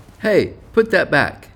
hey_put_that_back.wav